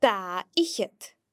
In most instances, the Gaelic fh sound is silent, with a few exceptions that we shall explore in a little bit.